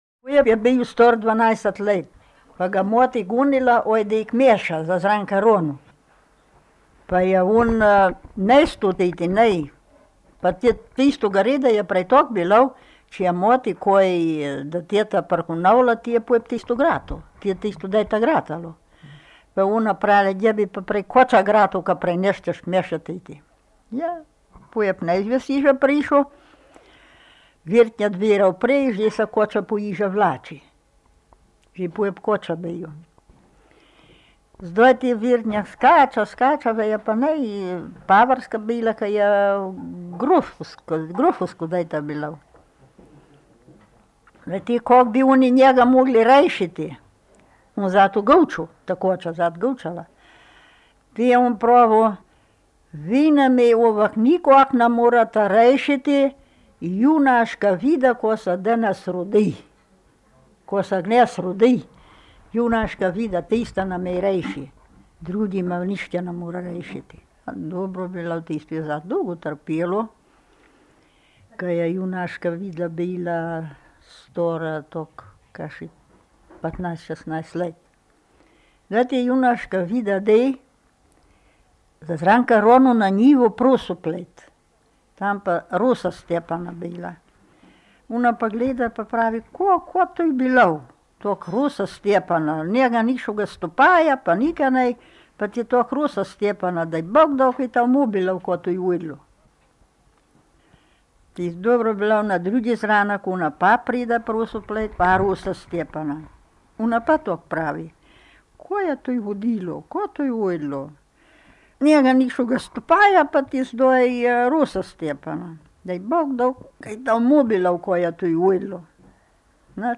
V knjigi so zbrane porabske pravljice in povedke, ki jih je leta 1970 posnel Milko Matičetov na magnetofonske trakove.
Dodana je zgoščenka s tonskimi posnetki trinajstih pravljic in povedk v obeh različicah porabskega narečja (števanovskem in gornjeseniškem).